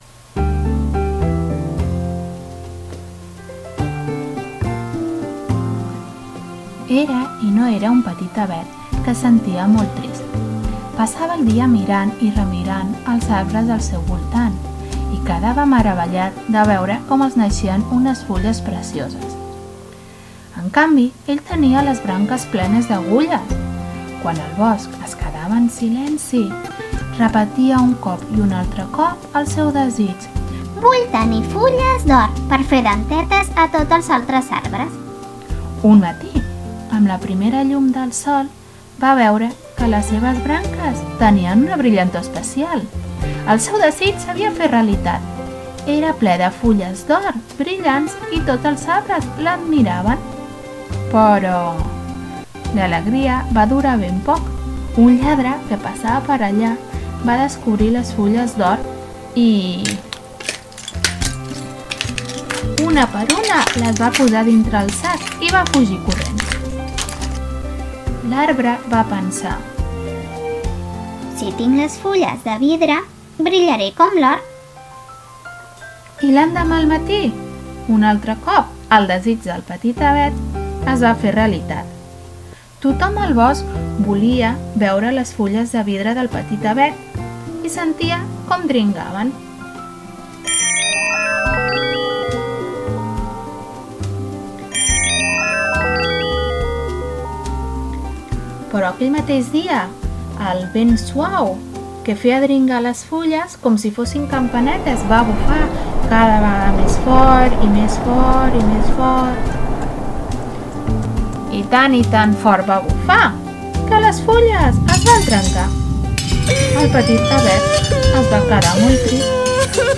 Conte El Petit Avet
conte_petit_avet1.mp3